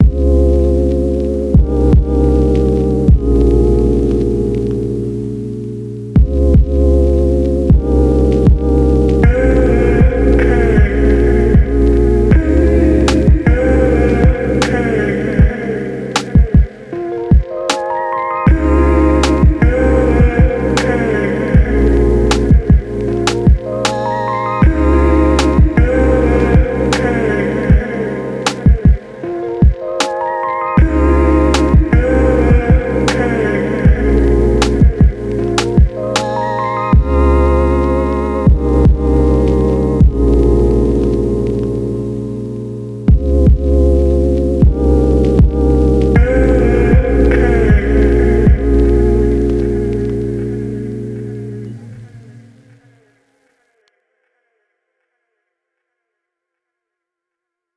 a lo-fi instrumental hip hop track